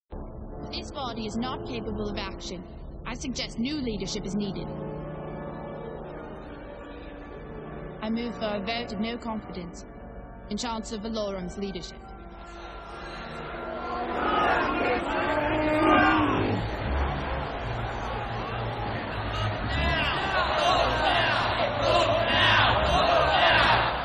Queen Amidala in the Galactic Senate — (audio)